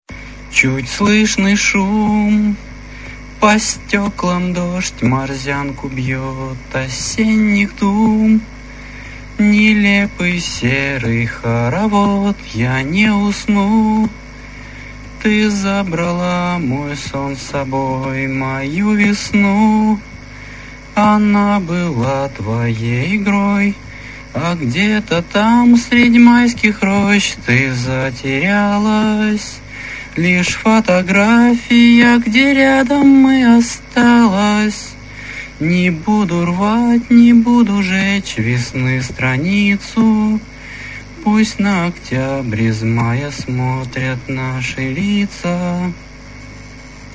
Песня
Прослушать в авторском исполнении (только вокал):